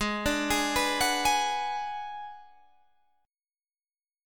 Abm7b5 Chord
Listen to Abm7b5 strummed